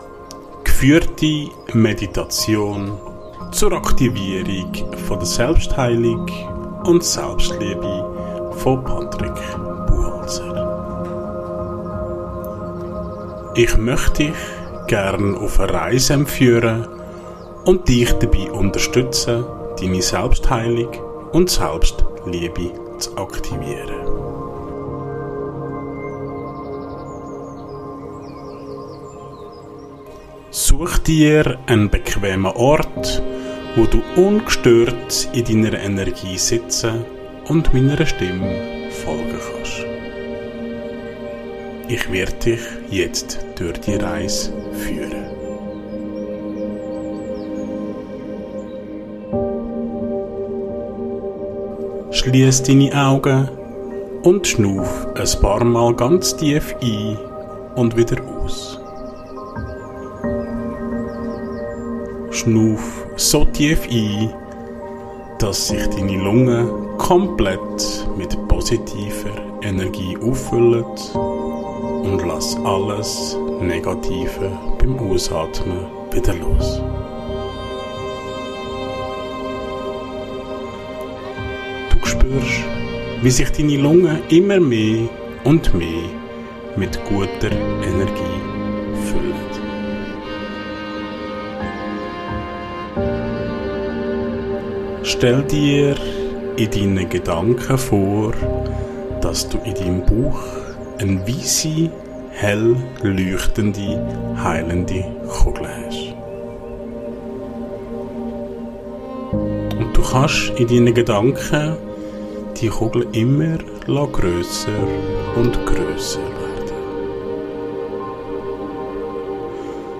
Geführte Meditation zur Aktivierung der Selbstheilung & Selbstliebe
In einem sicheren Raum begleite ich dich Schritt für Schritt durch sanfte Atemübungen, liebevolle Worte und stille Momente der Achtsamkeit. Gemeinsam aktivieren wir deine Selbstheilungskräfte – die natürlichen Fähigkeiten deines Körpers und deiner Seele, sich zu regenerieren, loszulassen und zu wachsen.